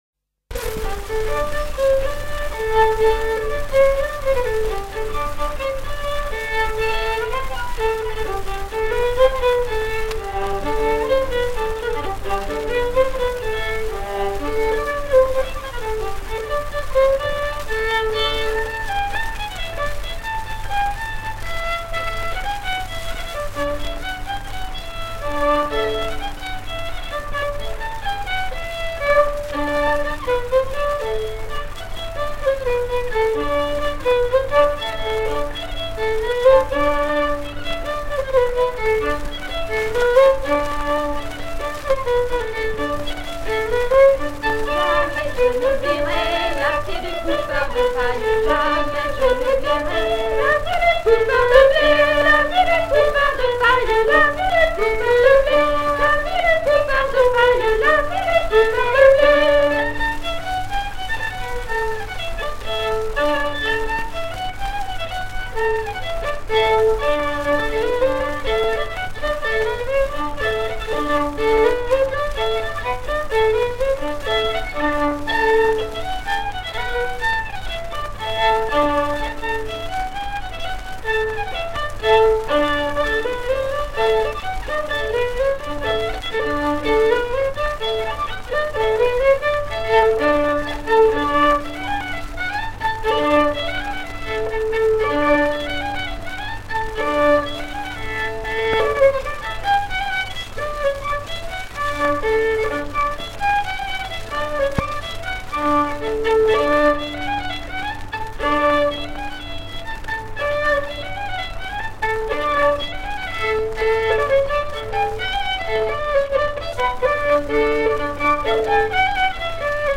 78 t pyral
danse : branle : courante, maraîchine
Pièce musicale inédite